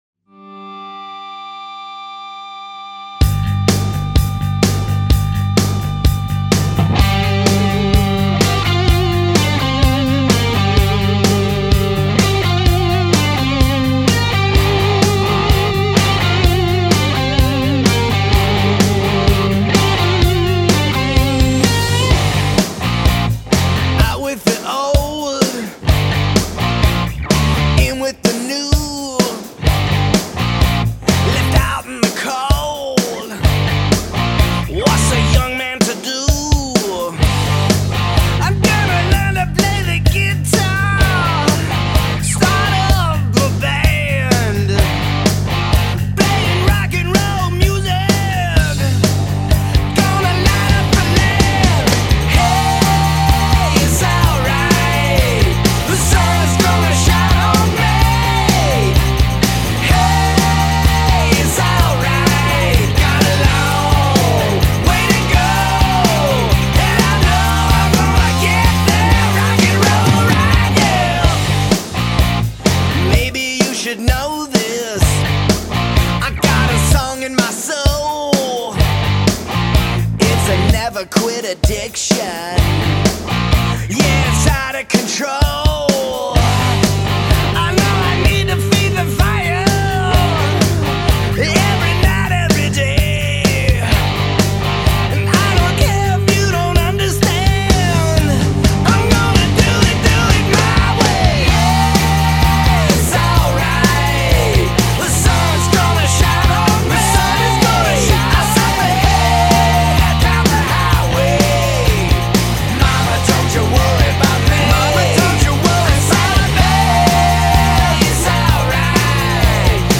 フックの効いたアメリカン・ハード・ロックがかっこ良すぎます。